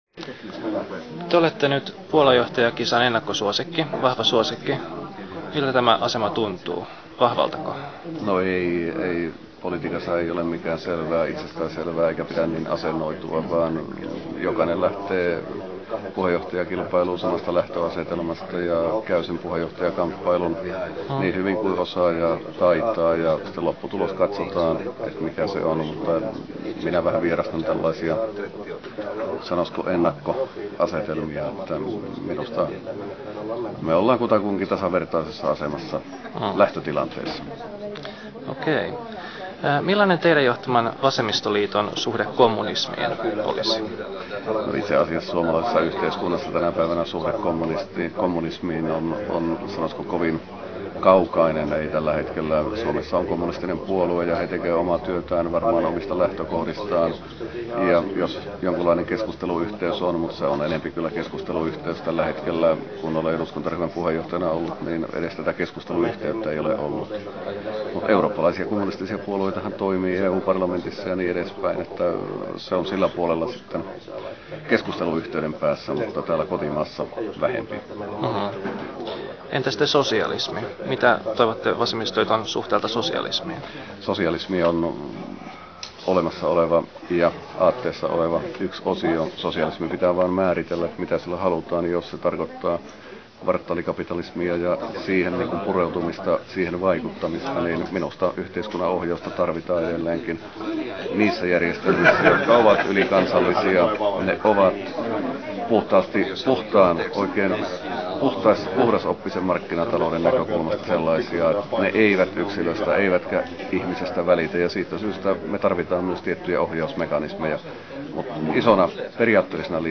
Puheenjohtajaehdokas ja eduskuntaryhmän puheenjohtaja Martti Korhonen vastaa Kulttuurivihkojen erikoishaastattelussa kiperiin kysymyksiin kommunismista, sosialismista ja puheenjohtajakilvasta.